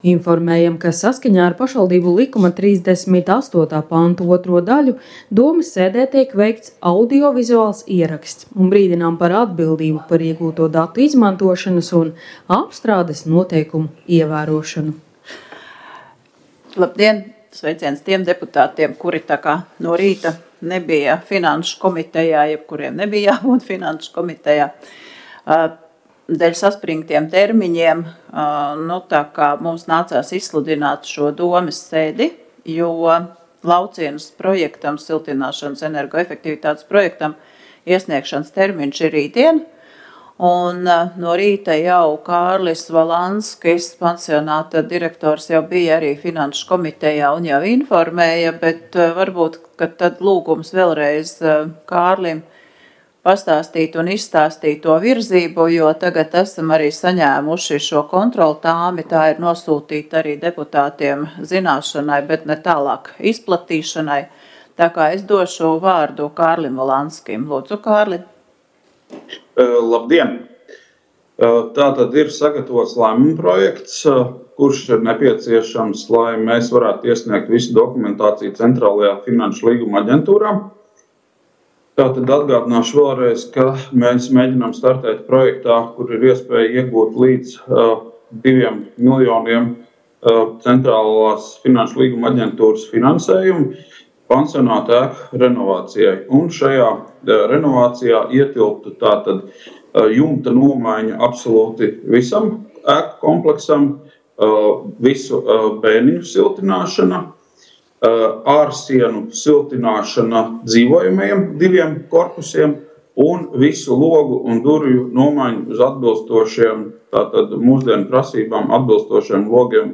Talsu novada domes sēde Nr. 9
Domes sēdes audio